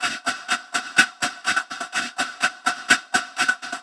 tx_perc_125_grungehats.wav